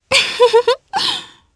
Selene-Vox_Happy2_jp.wav